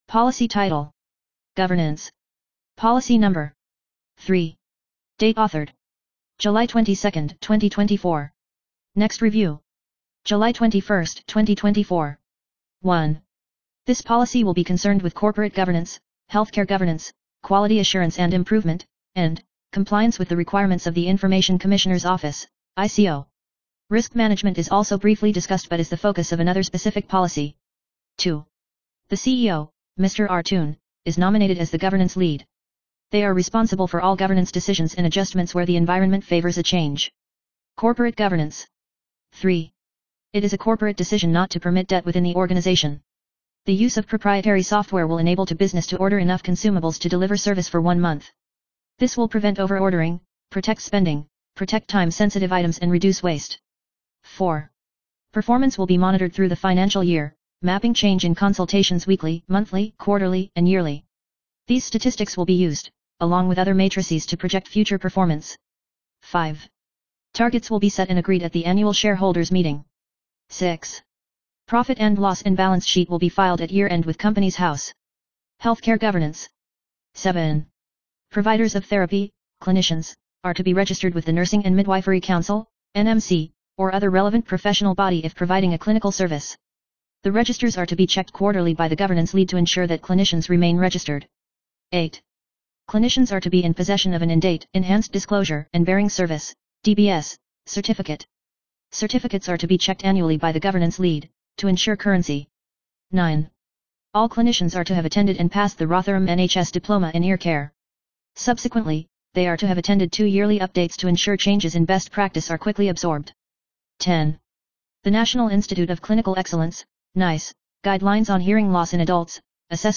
Narration of Governance Policy